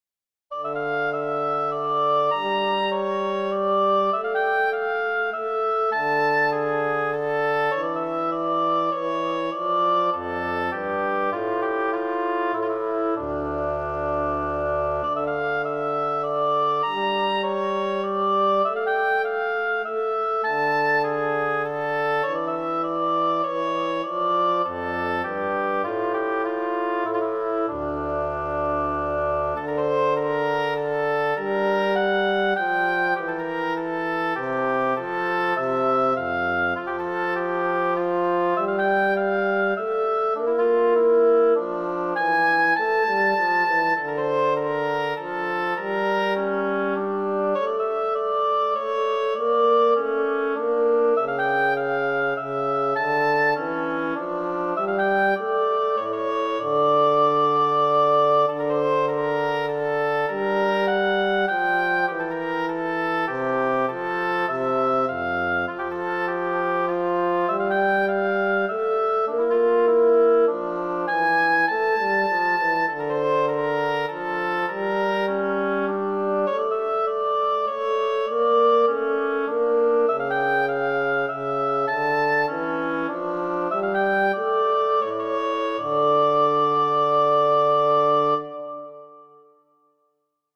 arranged for Oboe and Bassoon